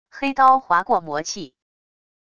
黑刀划过魔气wav音频